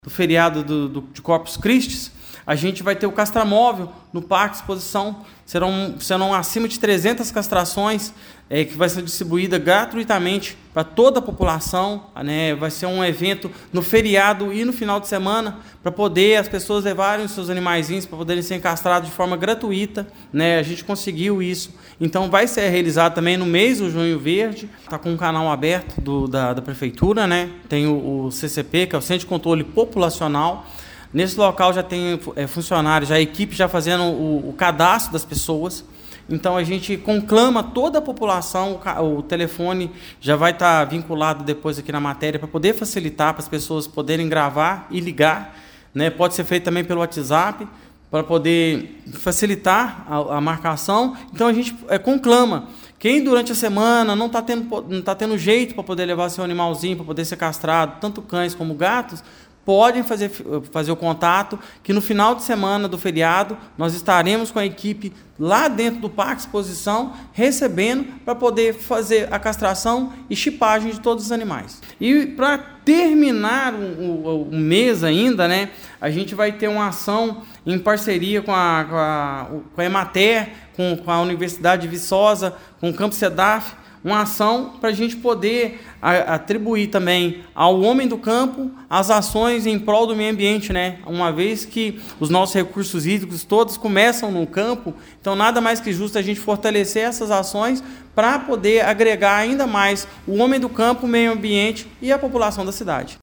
O secretário também ressaltou a importância de medidas que tornam mais ágil o processo de licenciamento ambiental na cidade. No dia 12, será apresentada uma minuta de decreto que desburocratiza e facilita as dispensas e licenças ambientais, incentivando o desenvolvimento sustentável sem abrir mão da preservação: